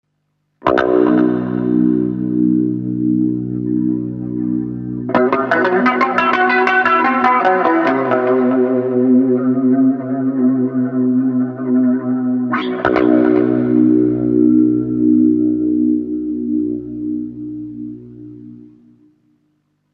無伴奏ギター独奏
スケール練習でもなんだか雰囲気でますな。
scalelesson.mp3